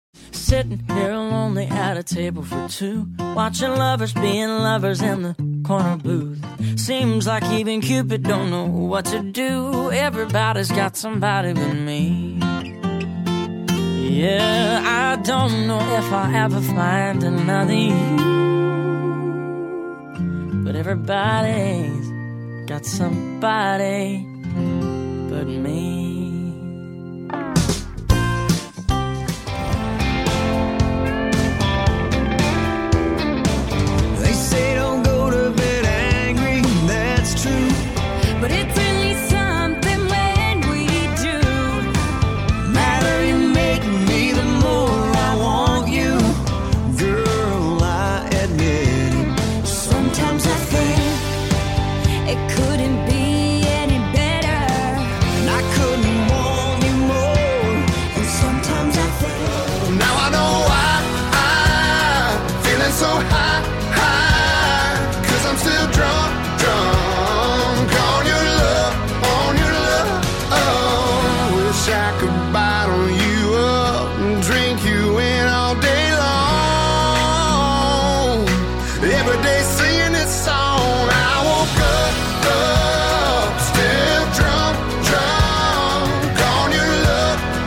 Popular Country Hits
Country Hits from Popular Artists